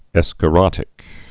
(ĕskə-rŏtĭk)